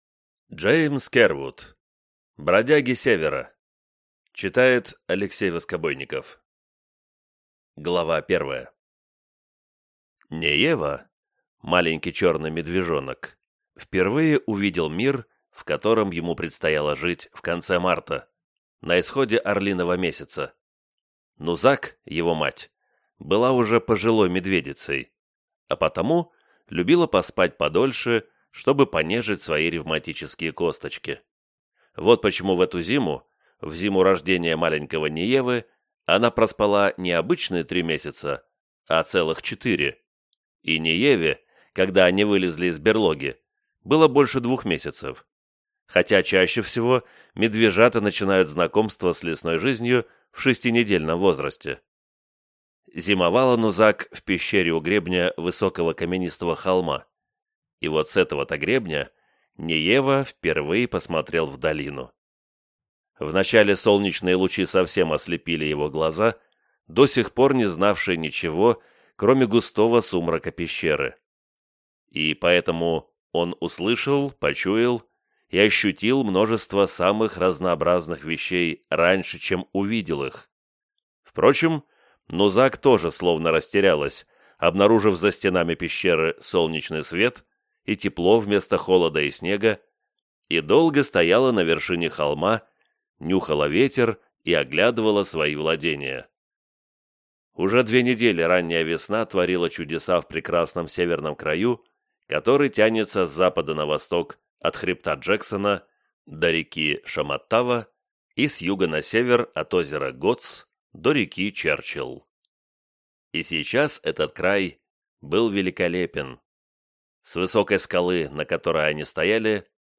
Аудиокнига Бродяги Севера | Библиотека аудиокниг